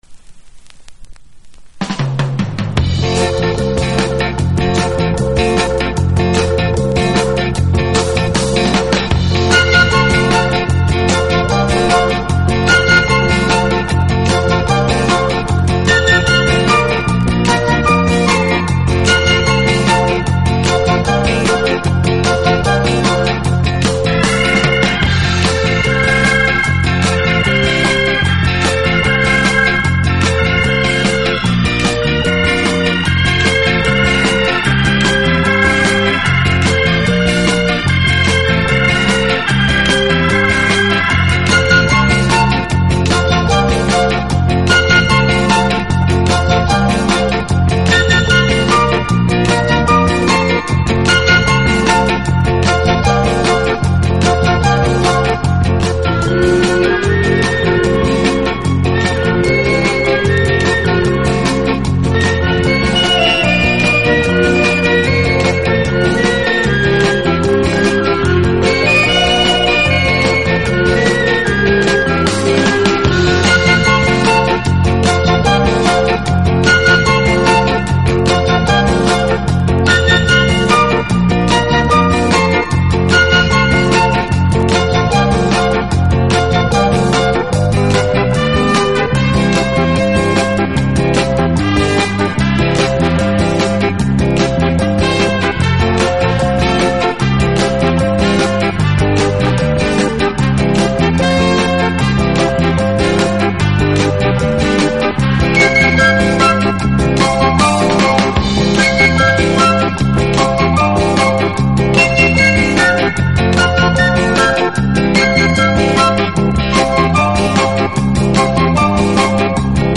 以擅长演奏拉丁美洲音乐而著称。
乐器的演奏，具有拉美音乐独特的韵味。